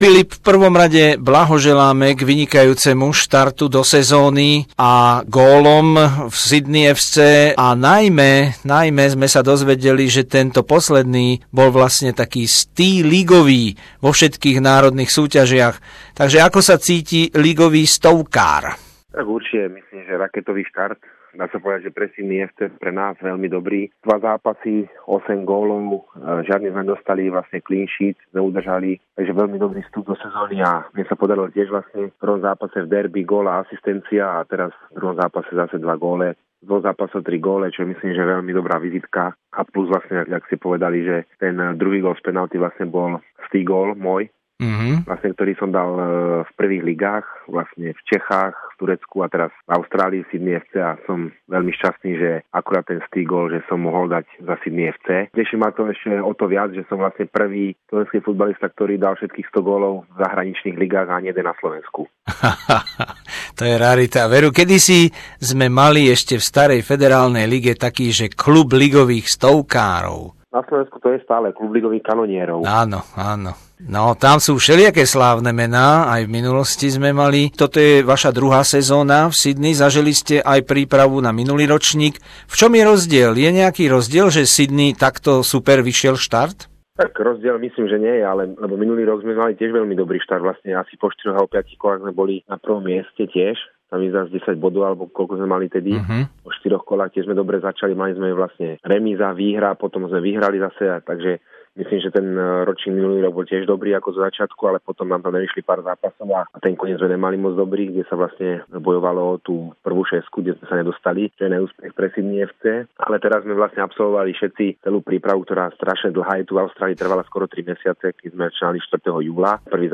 Rozhovor so slovenským futbalistom v drese Sydney FC Filipom Hološkom po strelení jeho stého gólu v ligových súťažiach v troch rôznych krajinách (Česko, Turecko, Austrália)